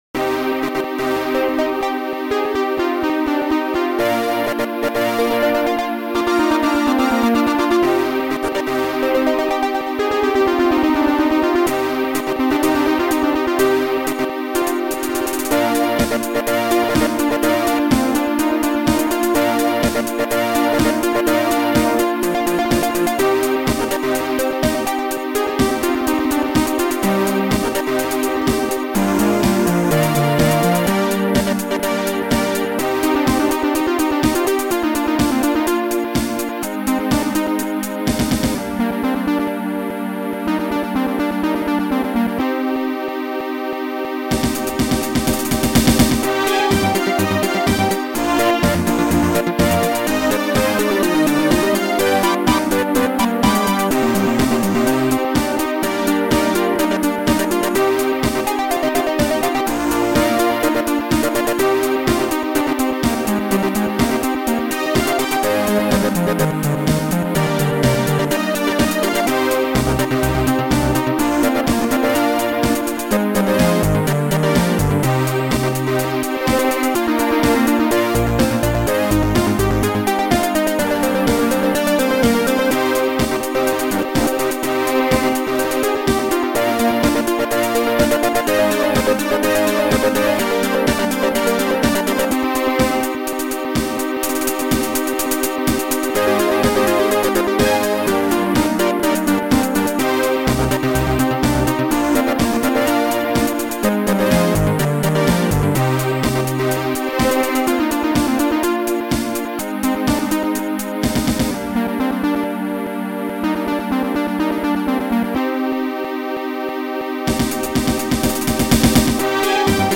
Sound Format: Noisetracker/Protracker
Sound Style: Mellow